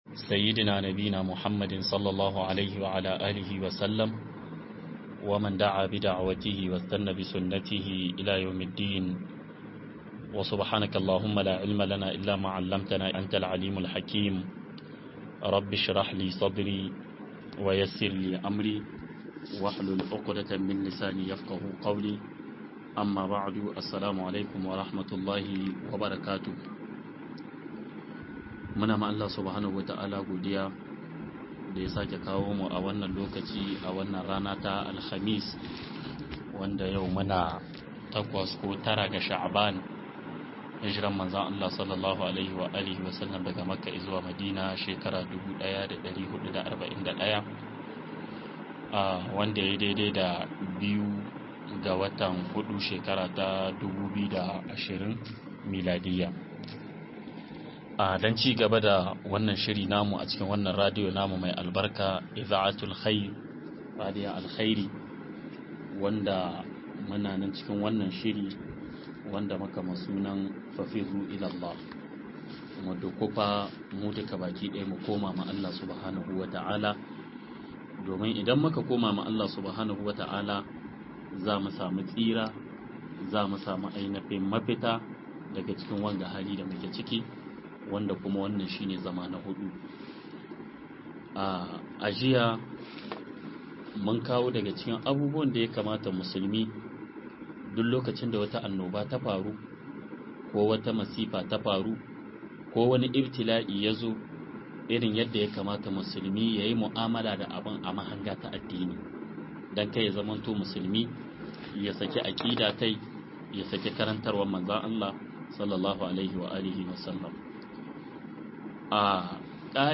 MUKOMA-ZUWA-GA-ALLAH-SHINE-MAFITA-04 - MUHADARA